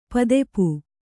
♪ padepu